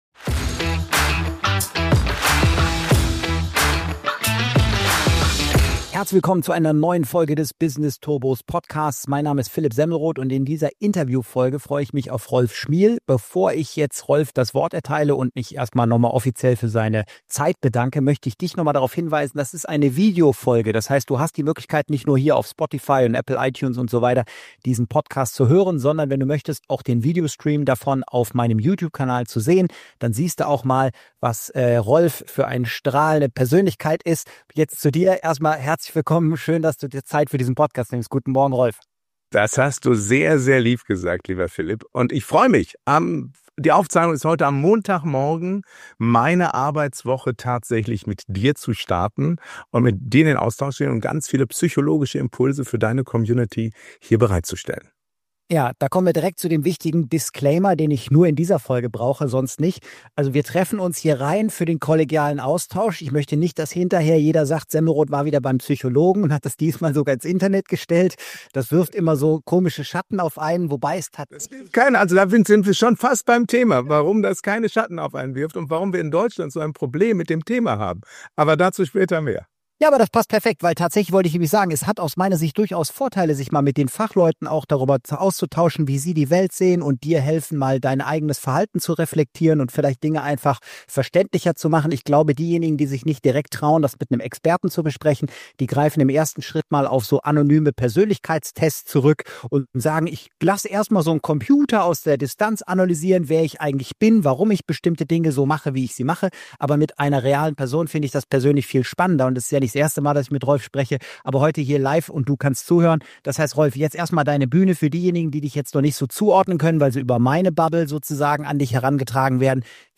#103 - INTERVIEW